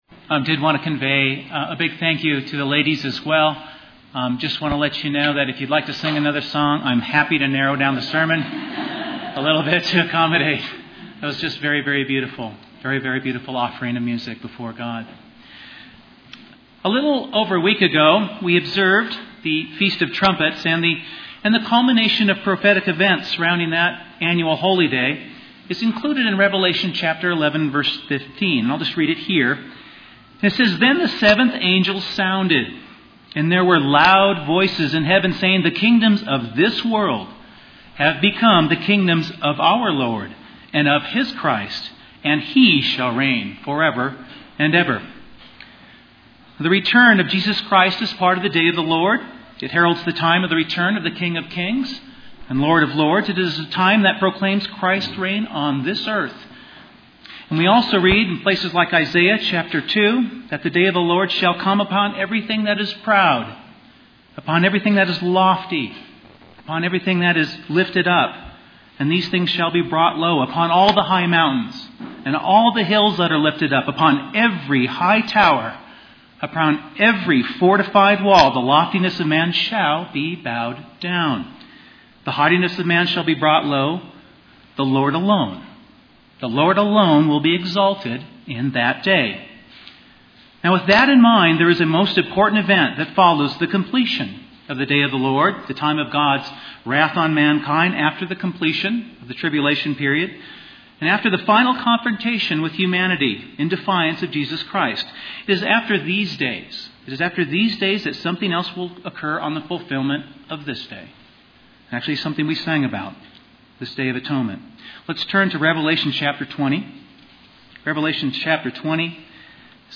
The sermon reviews the meaning and symbolism of the Day of Atonement with a focus on a future time when only God will be exalted.
Given in Colorado Springs, CO